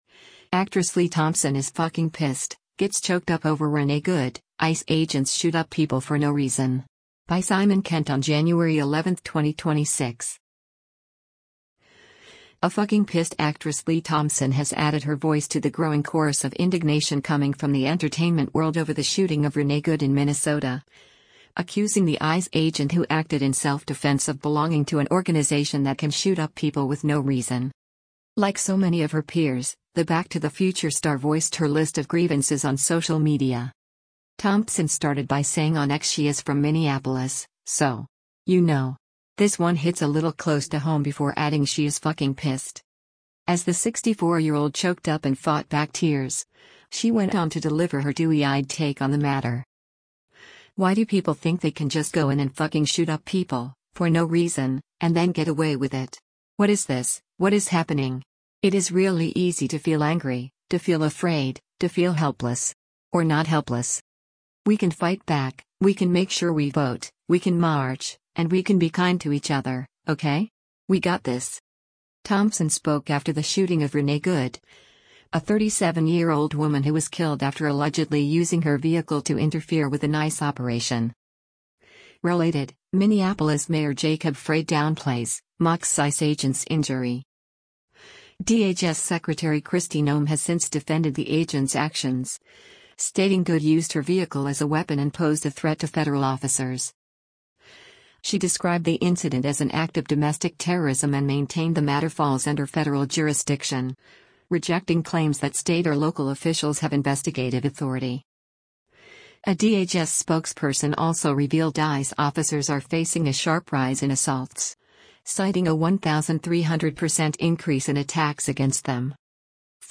Like so many of her peers, the Back to the Future star voiced her list of grievances on social media.
As the 64-year-old choked up and fought back tears, she went on to deliver her dewy-eyed take on the matter.